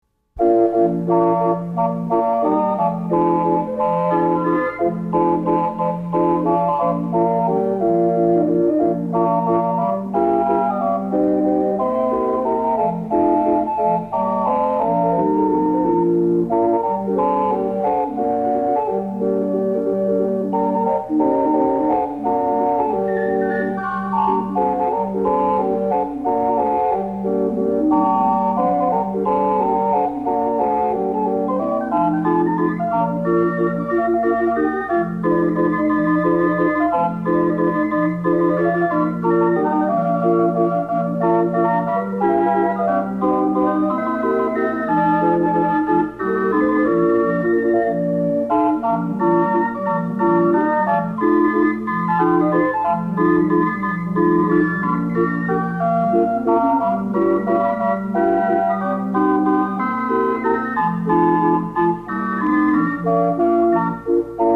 AUTHENTIC CIRCUS CALLIOPE MUSIC